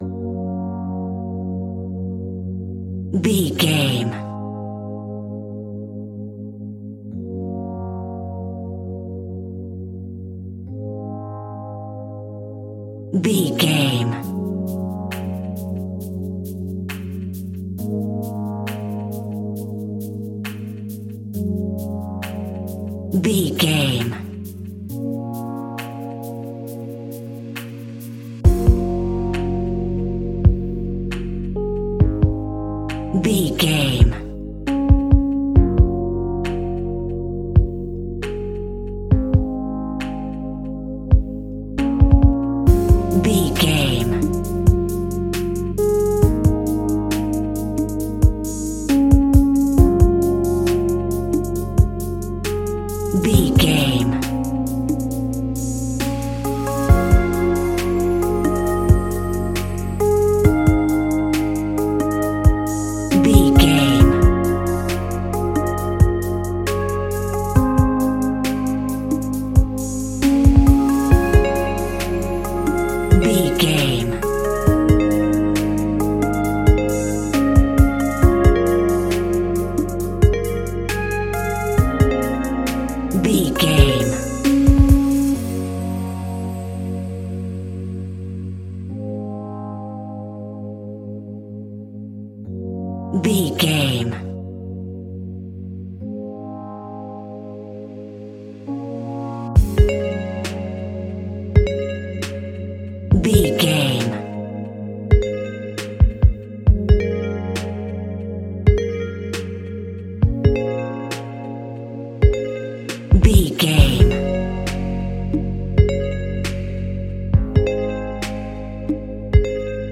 Aeolian/Minor
B♭
Slow
Elecronica Music
laid back
groove
hip hop drums
hip hop synths
piano
hip hop pads